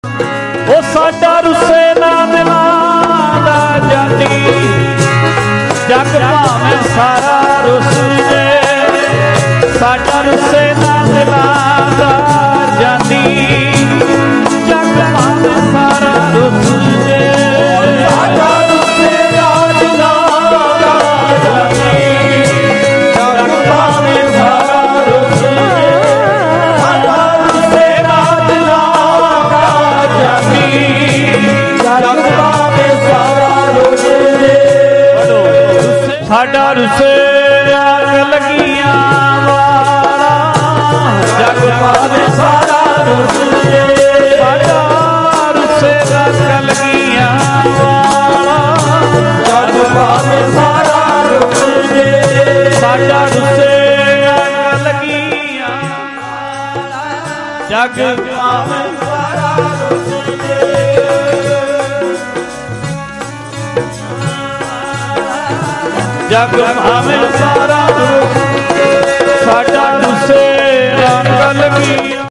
One day Samagam at Majhu Ke Barnala